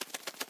1.21.5 / assets / minecraft / sounds / mob / parrot / fly1.ogg
fly1.ogg